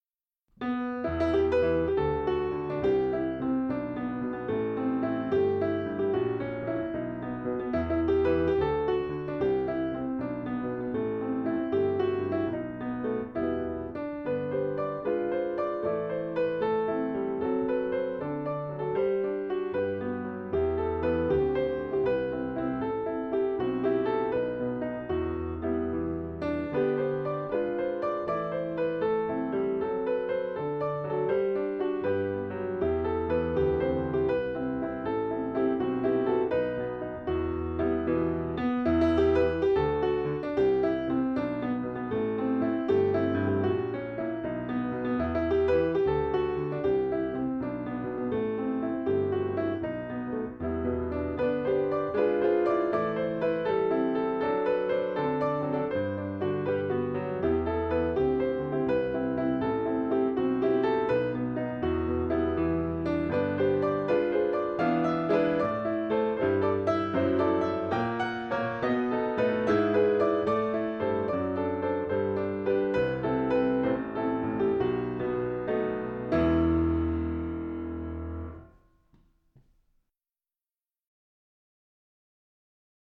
Endast piano (